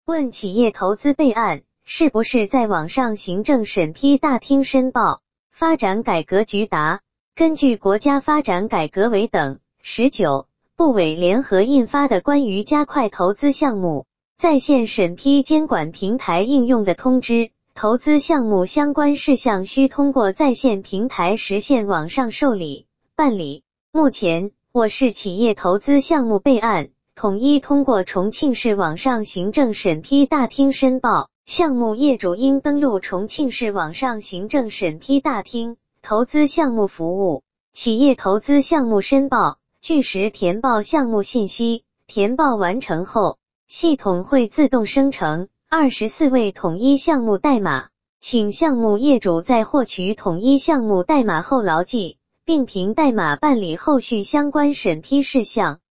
语音播报